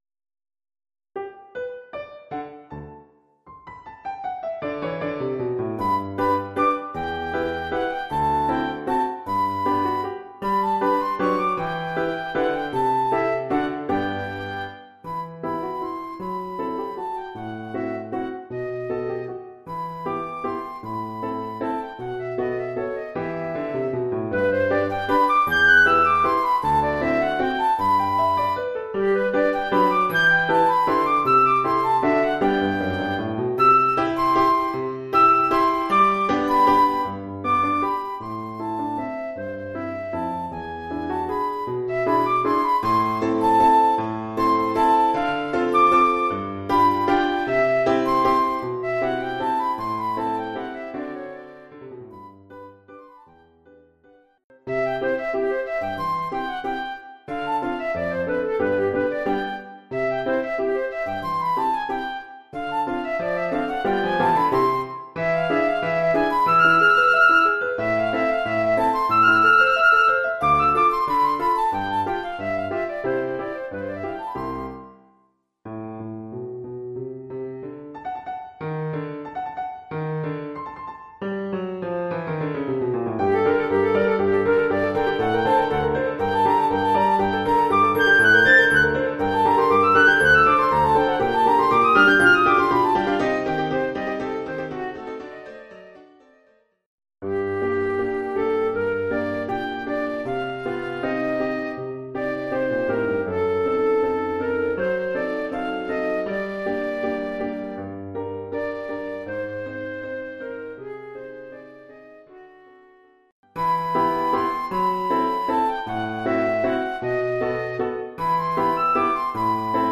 Formule instrumentale : Flûte et piano
avec accompagnement de piano".